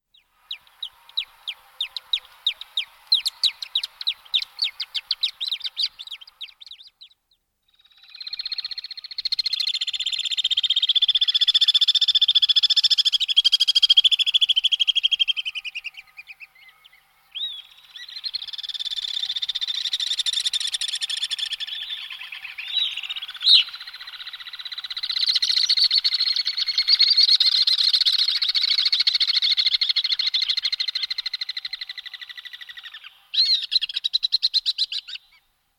На этой странице собраны звуки, издаваемые стрижами: их звонкое щебетание, крики в полёте и другие природные голоса.
Звук белобрюхого стрижа